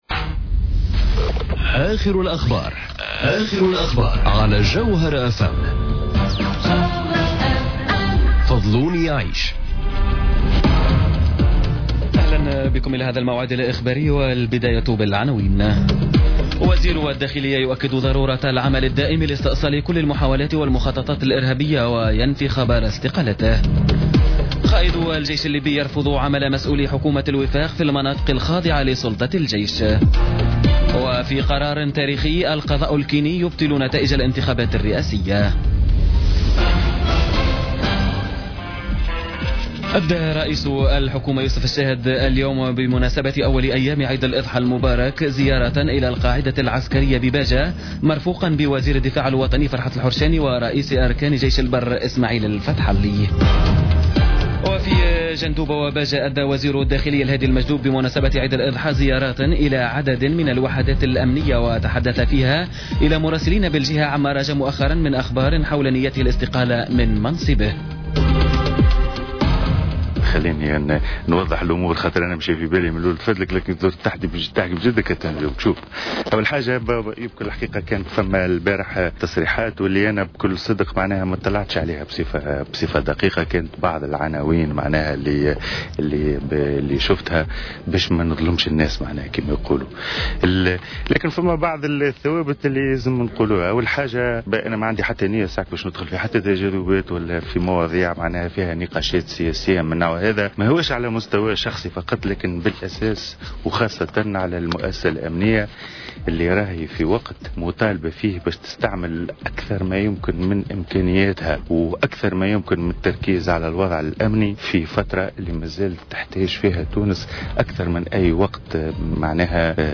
نشرة أخبار السابعة مساء ليوم الجمعة غرة سبتمبر 2017